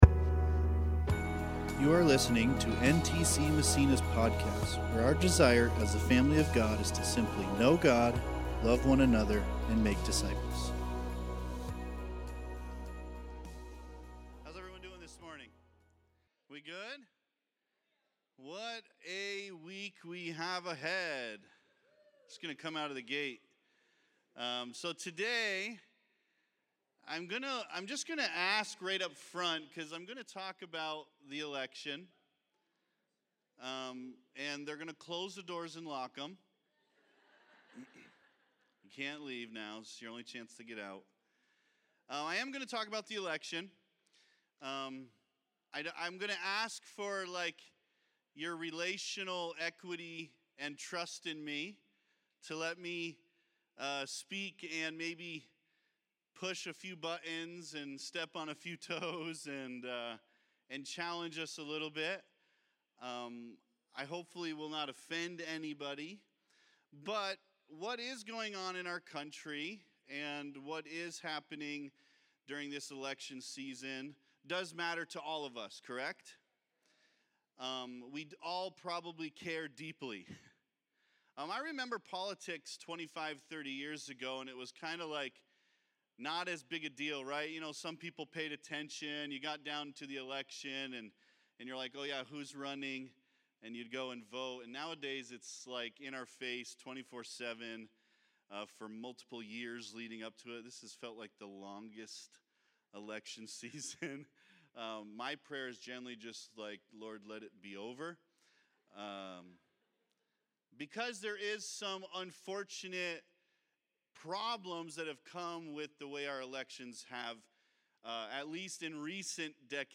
2024 Growing W.7- Honor Preacher